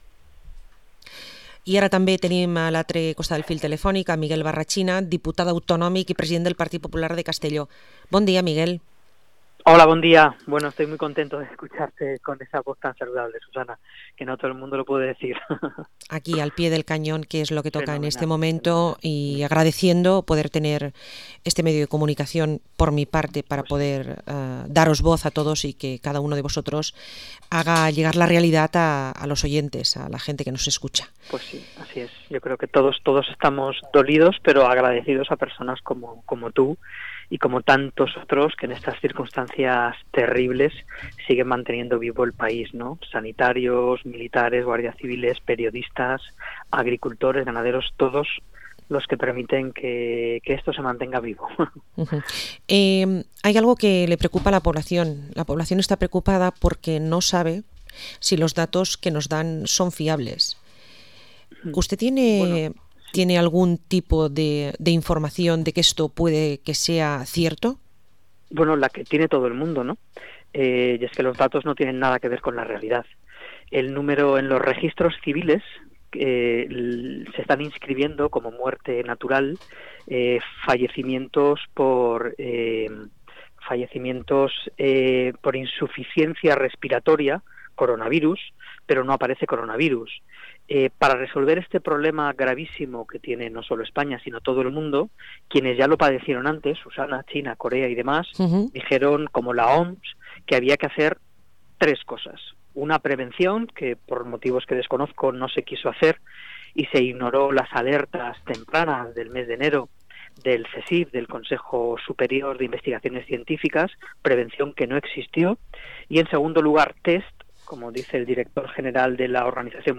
Entrevista al diputado en las Cortes valencianas y presidente del PP a Castellón, Miguel Barrachina